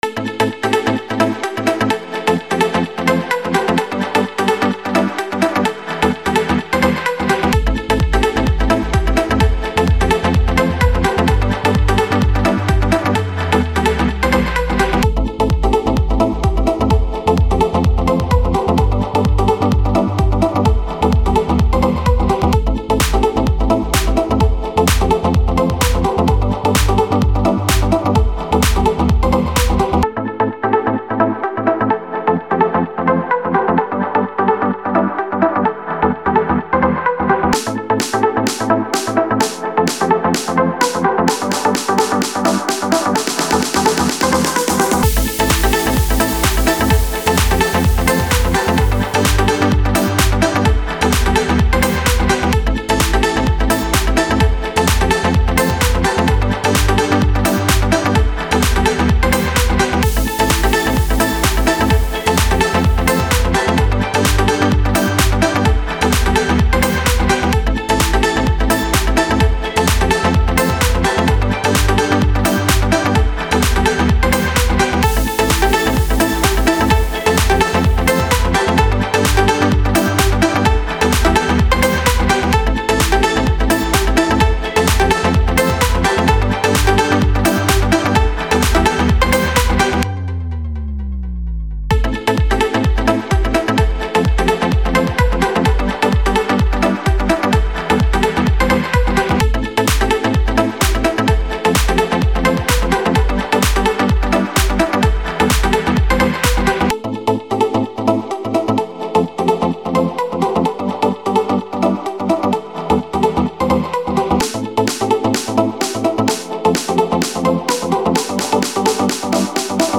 минусовка версия 177785